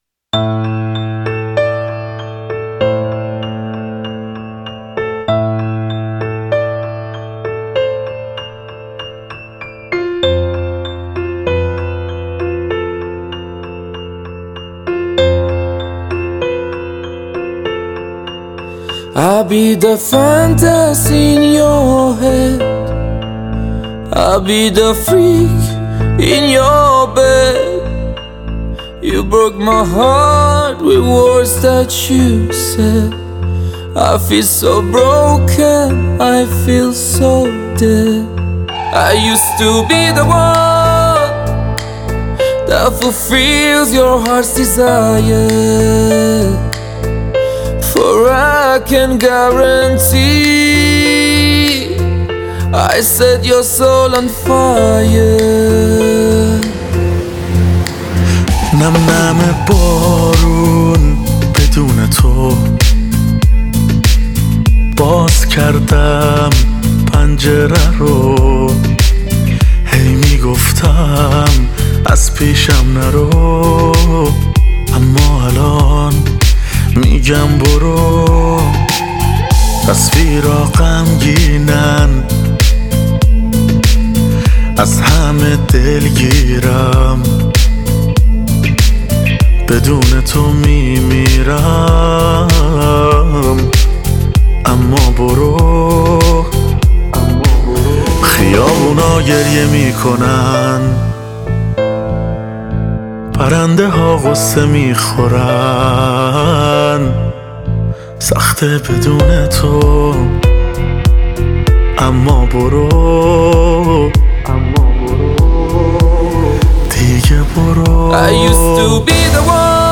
پاپ
آهنگ غمگین